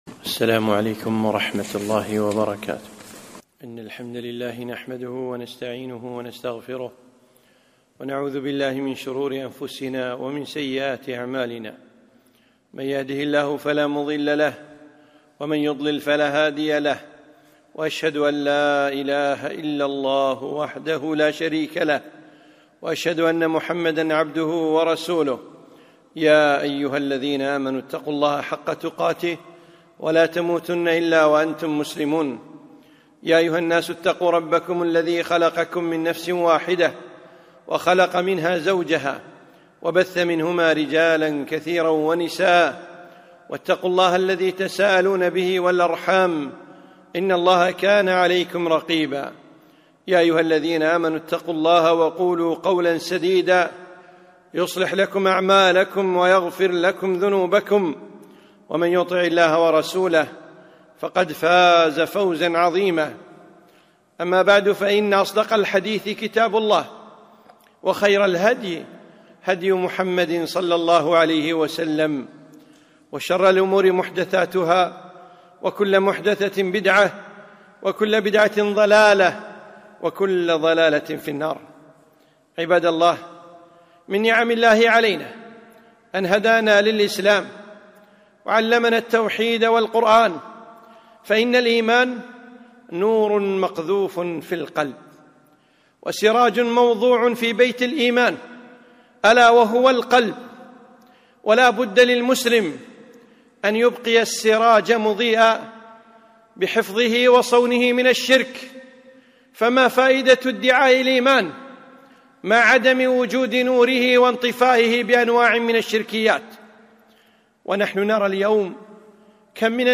خطبة - احذر أن يطفأ نورك